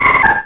Cri de Tarsal dans Pokémon Rubis et Saphir.